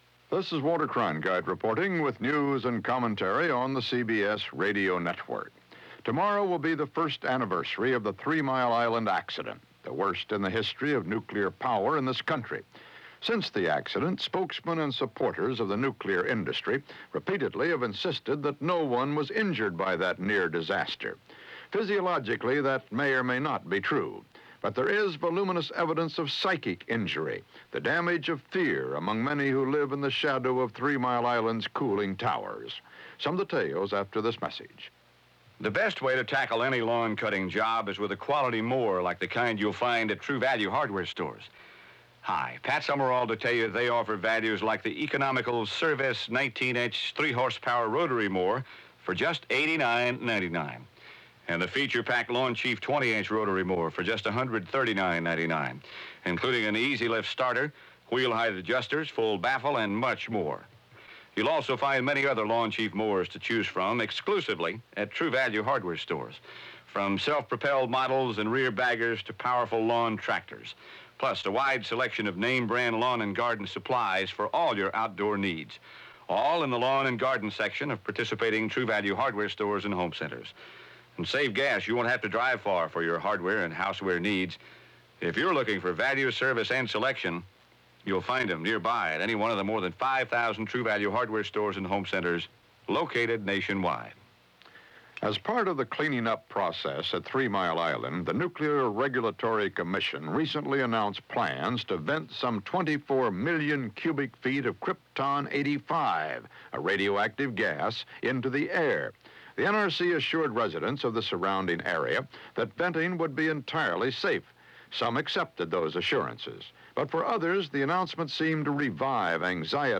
Anniversary Of A Debacle - Three Mile Island At One Year - March 27, 1980 - news and reports on the one year anniversary.
Walter Cronkite News and Commentary
KNX Documentary